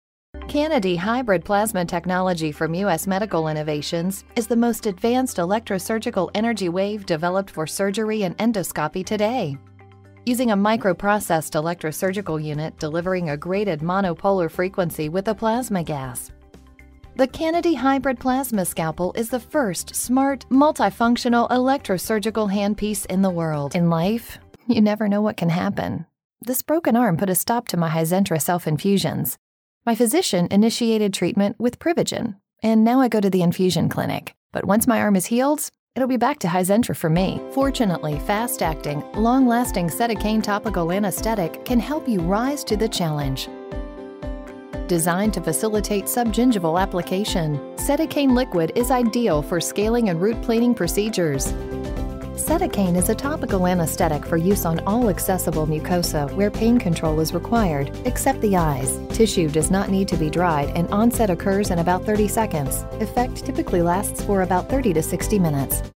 Female Voice Over, Dan Wachs Talent Agency.
Warm, Authoritative, Spokesperson.
Medical